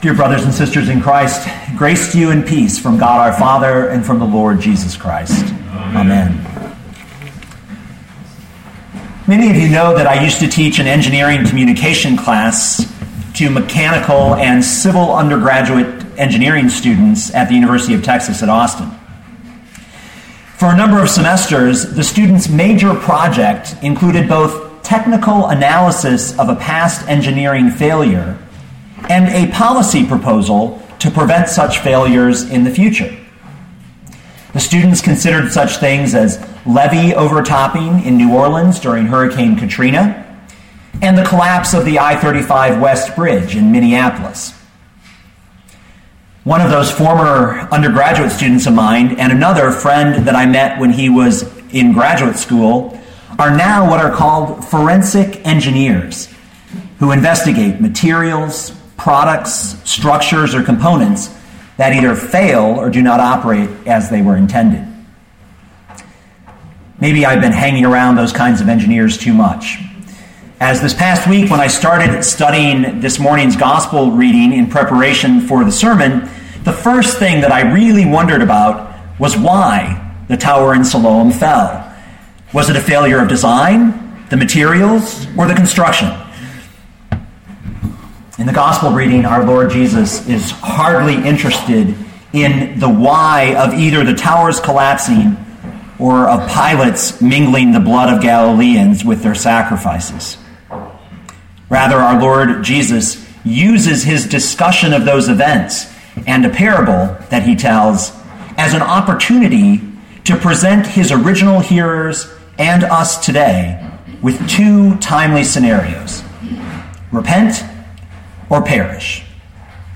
2013 Luke 13:1-9 Listen to the sermon with the player below, or, download the audio.